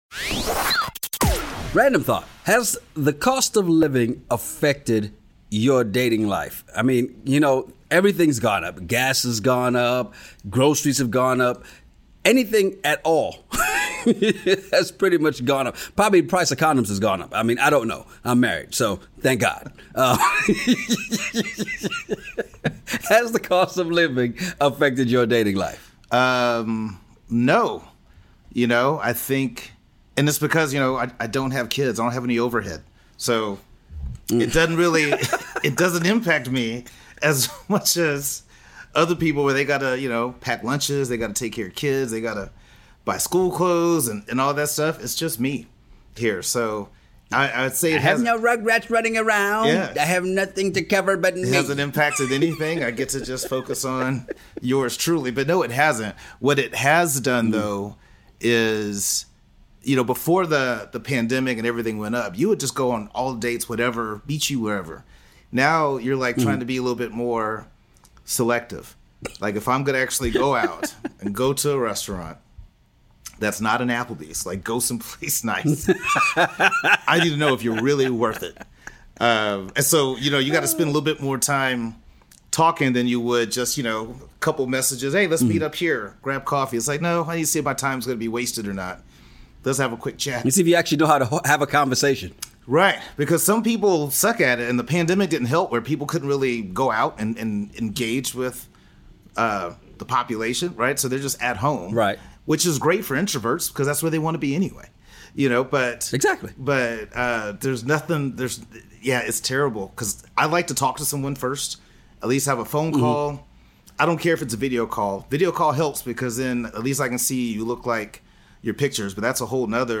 Has the cost of living affected your dating life? Ever been somewhere and overheard two guys having a crazy conversation over random topics?